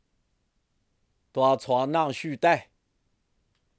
18踏车打水碓